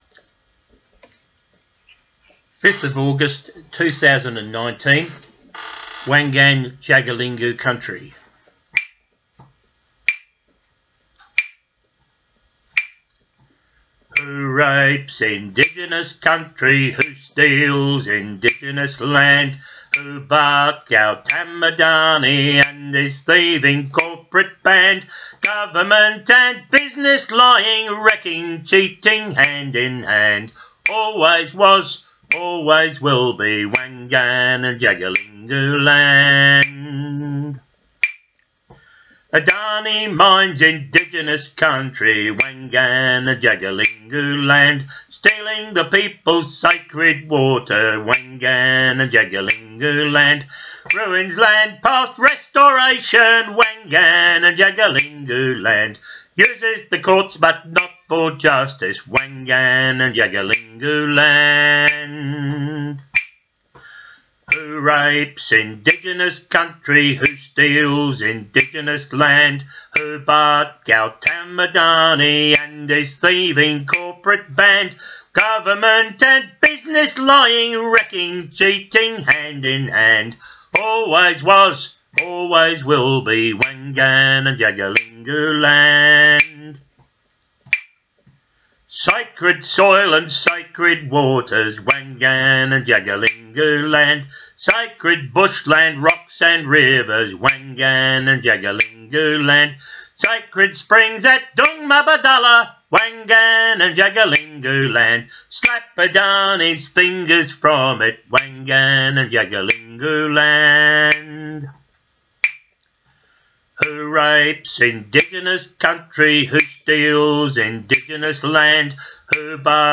protest song